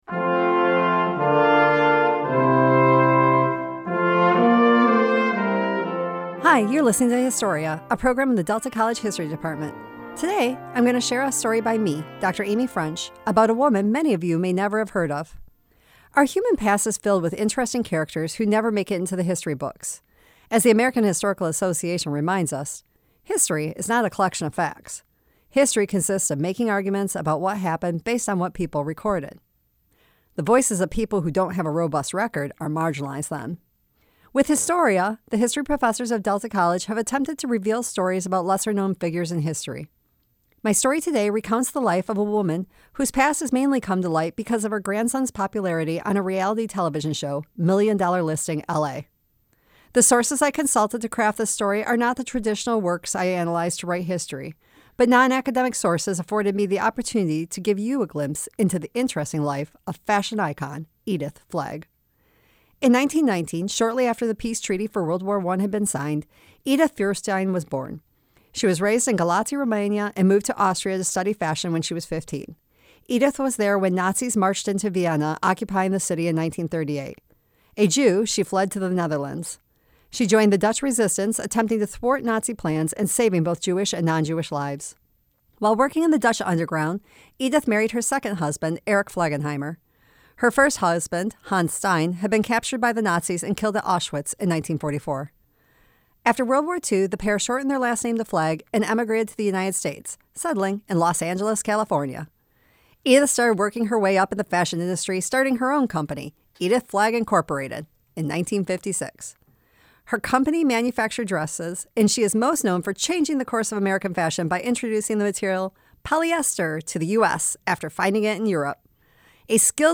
Historia, a program that airs twice weekly on a local Public Broadcasting/NPR affiliate radio station, FM 90.1, in Michigan.